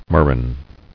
[mur·rain]